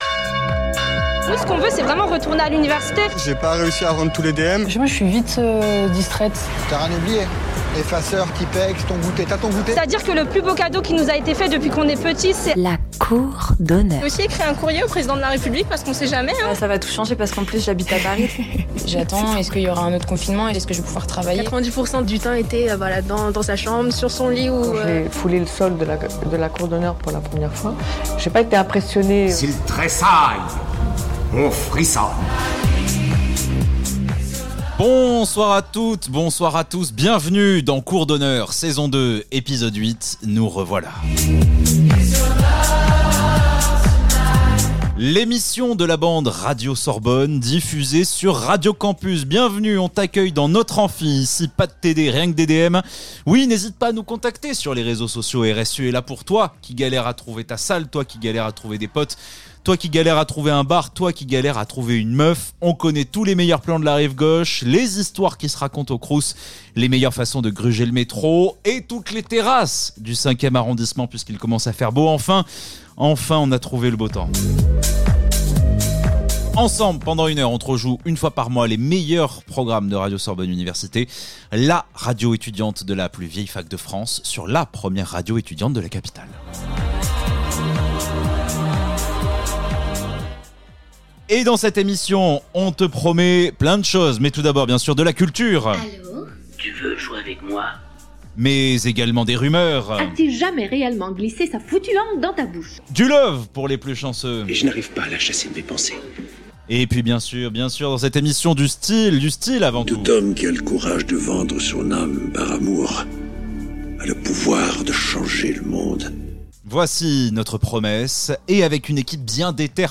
Cour d'Honneur #17 : Avril / Saison II Episode 8 Les étudiants de Radio Sorbonne Université piratent Radio Campus Paris Retour en studio ! Comme chaque mois Cour d'Honneur fait le tour de la vie étudiante à la Sorbonne sous toutes ses facettes.
Les étudiants de Radio Sorbonne Université piratent Radio Campus Paris